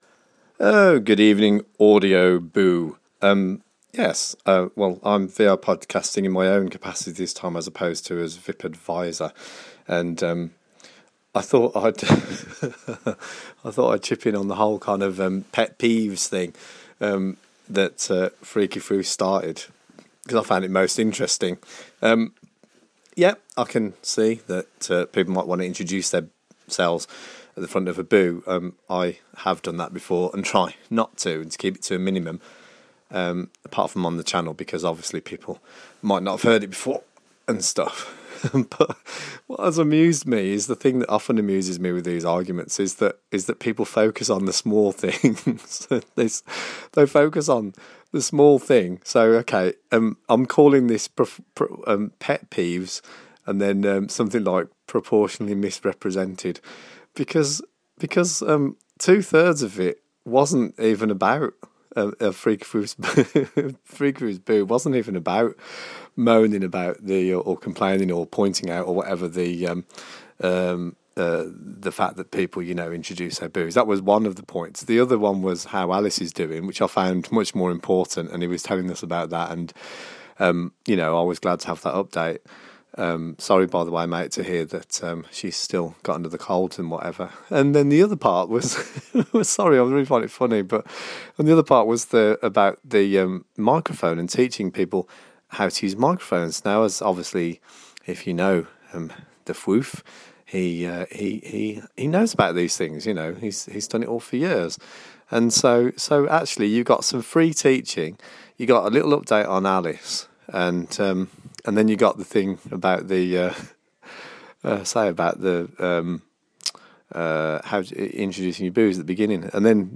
Warning, this Boo contains me laughing at my own observations.